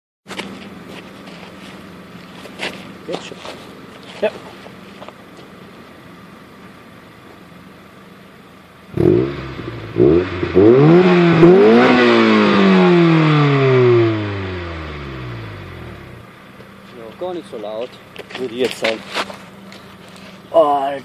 Tanbae Anlage auf meinem CRX ED9
joar hört sich gut an.
ähnlich wie meine greddy =)